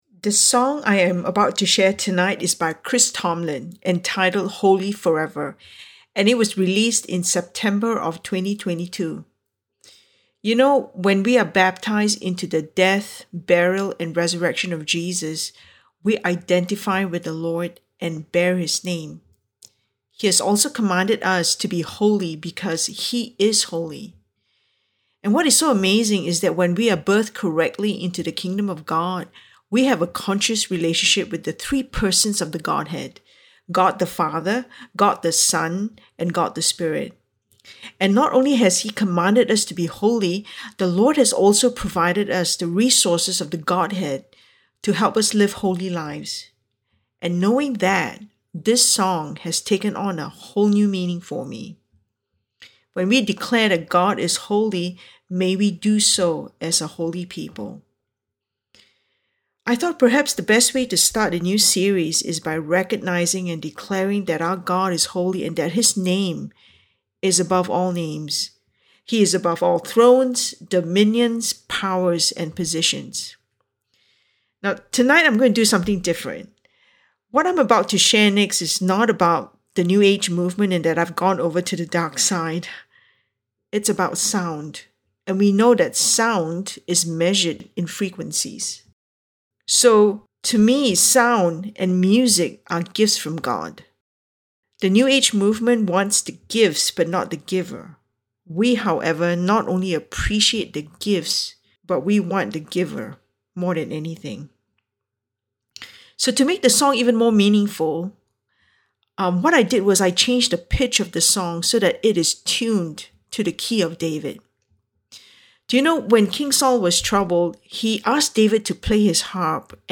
A message from the series "Spiritual Body Building."